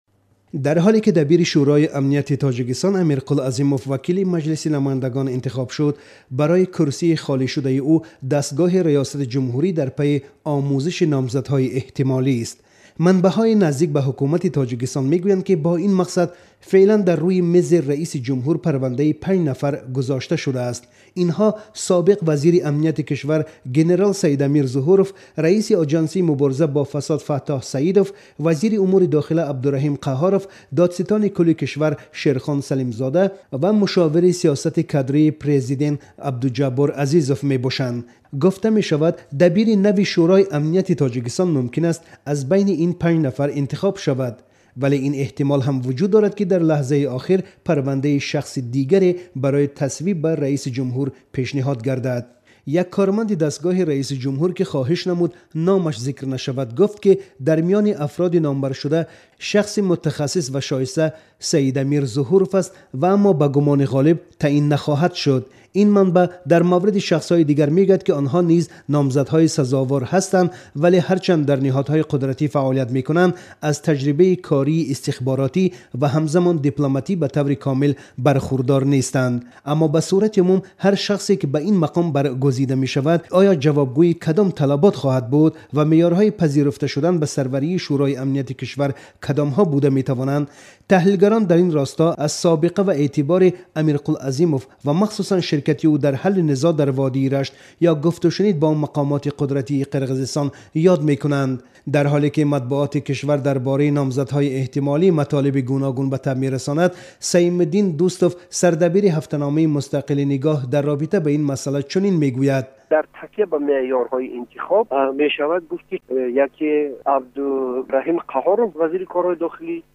Гузориши